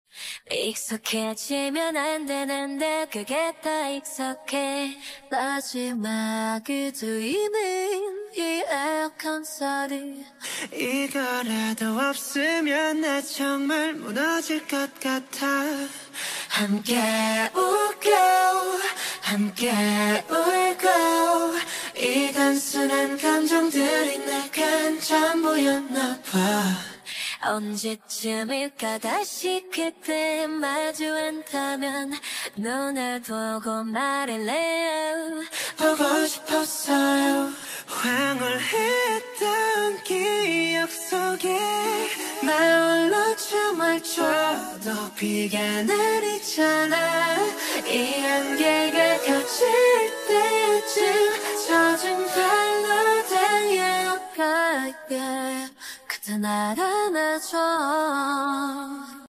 ai cover
acapella